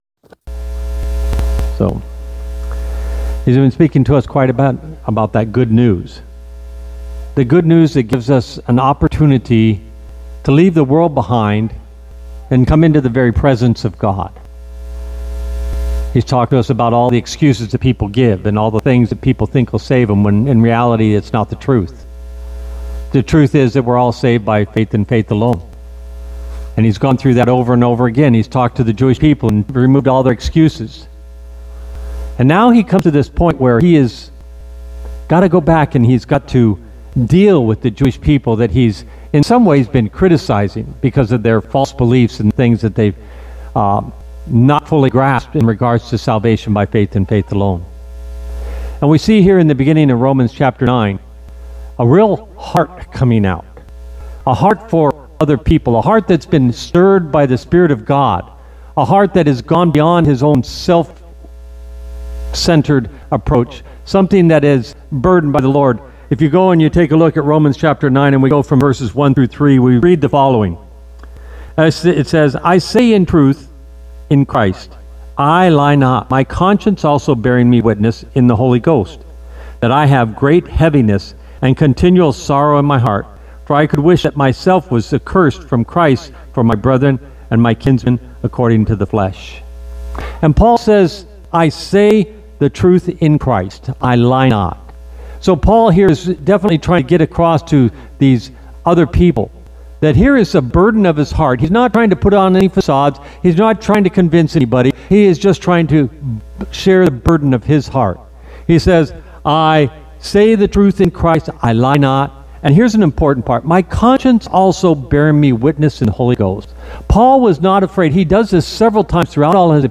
All Sermons Romans 9:1-9 Dying to reach the lost 26 May 2025 Series: Romans Topic: Reaching the lost Book